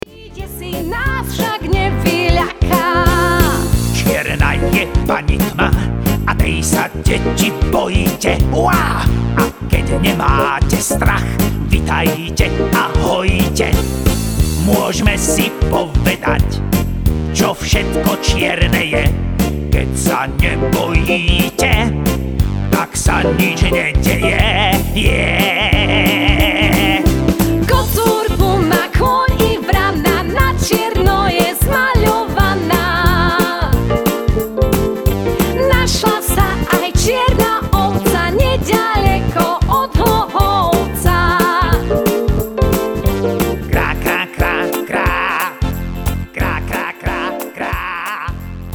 klarinet,saxofon,altová flauta
gitara
detský spev
deti zo ZUŠ v Malackách – zbor